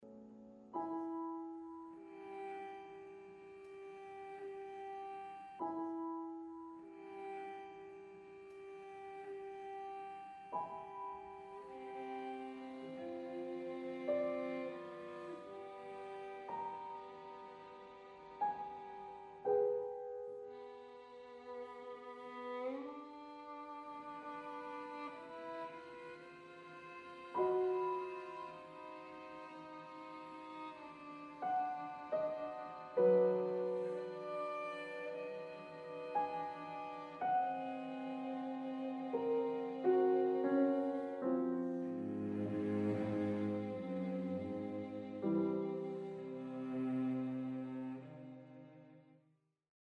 Andante
piano
violin
cello